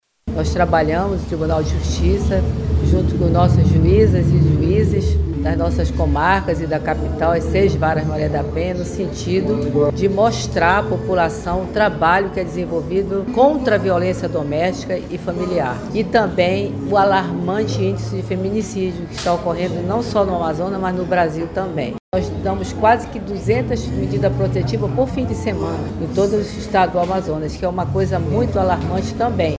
No estado, 1.900 processos foram pautados para a Semana, que envolverá os Juizados Maria da Penha na capital e as Varas do interior, como explica a desembargadora Maria das Graças Figueiredo.
Sonora-1-–-Maria-das-Gracas-Figueiredo.mp3